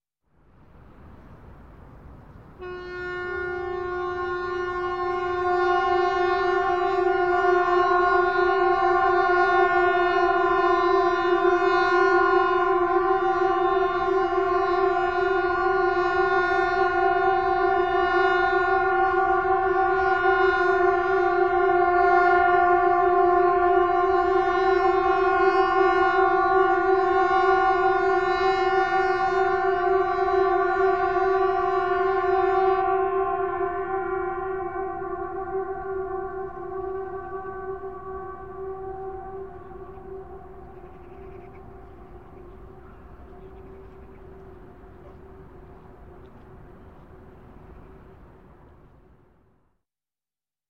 Звуки бомбёжек
Авиатревога в ряде стран